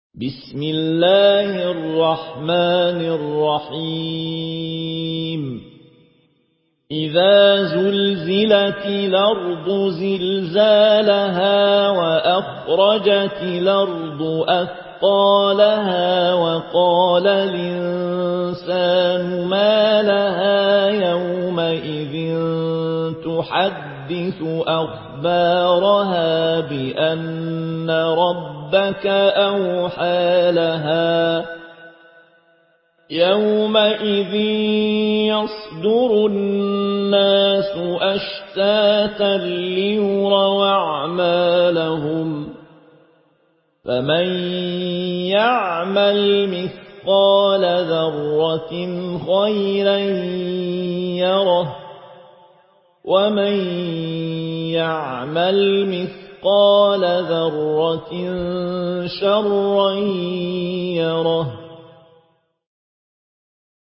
Une récitation touchante et belle des versets coraniques par la narration Warsh An Nafi.
Murattal